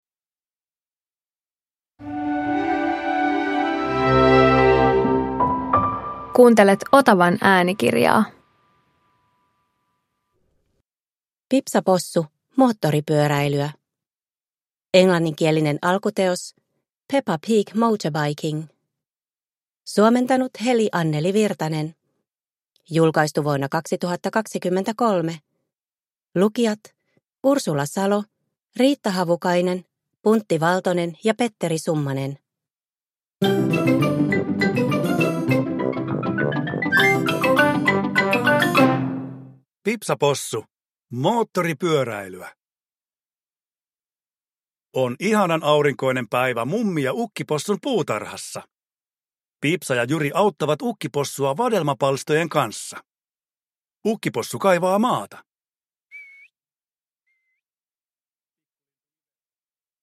Pipsa Possu - Moottoripyöräilyä – Ljudbok